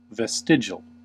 Ääntäminen
IPA : /vɛˈstɪdʒɪəl/ IPA : /vɛˈstɪdʒəl/